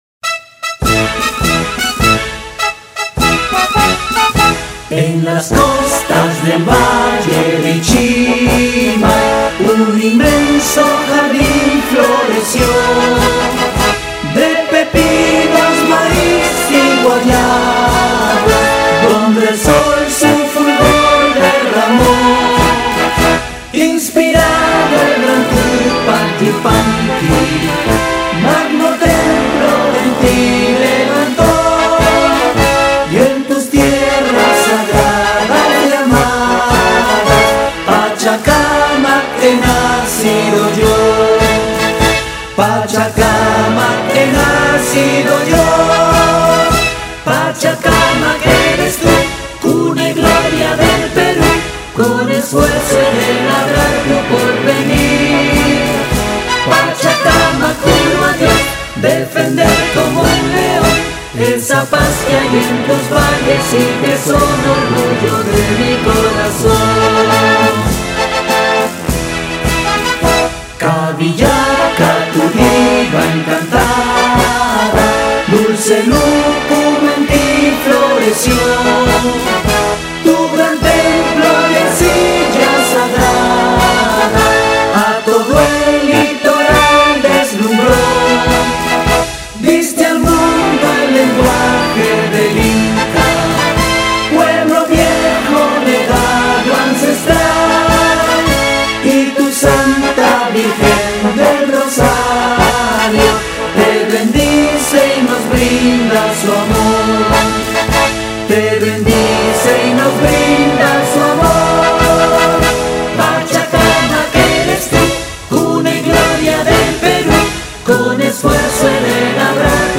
himno.mp3